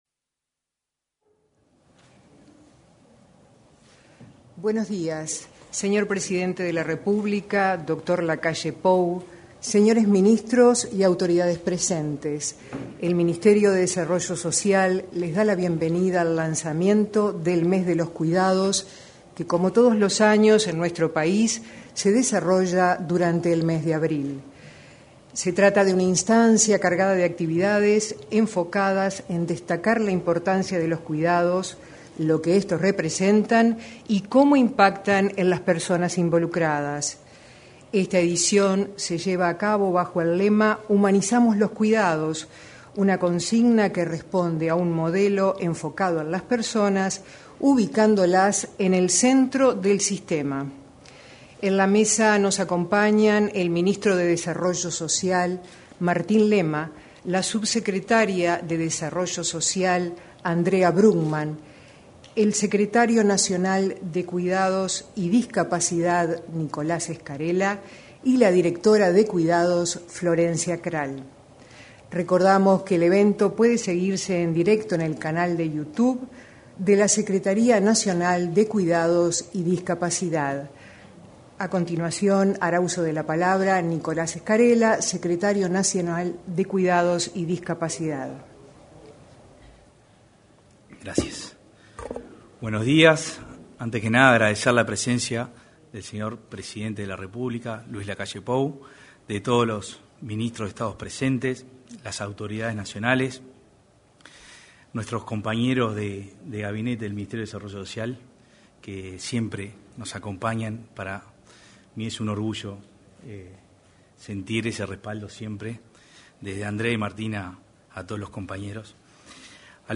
Lanzamiento del Mes de los Cuidados 05/04/2022 Compartir Facebook X Copiar enlace WhatsApp LinkedIn El presidente de la República, Luis Lacalle Pou, asistió al lanzamiento del Mes de los Cuidados, con la participación del secretario nacional de Cuidados y Discapacidad, Nicolás Scarela, la directora Nacional de Cuidados, Florencia Krall, y el ministro de Desarrollo Social, Martín Lema.